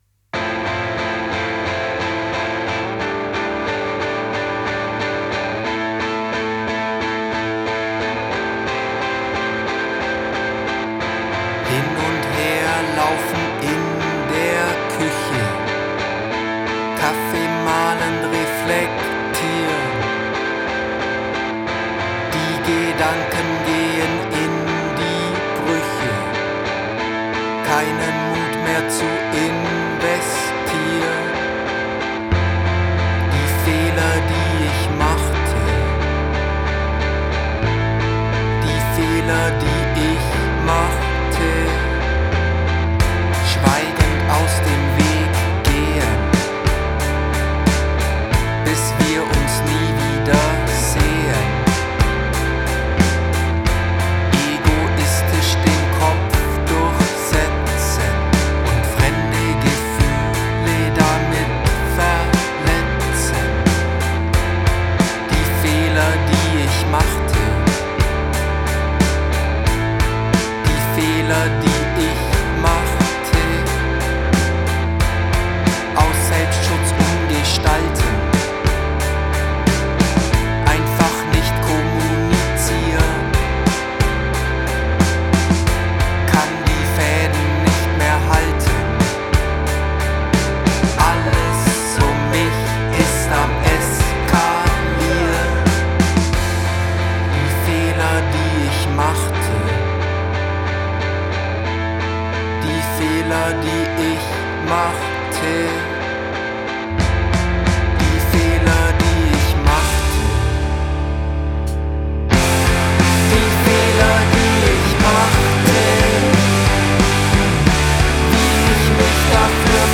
Gitarre
Bass
Schlagzeug
Es wird wird laut, verrückt, schrammlig.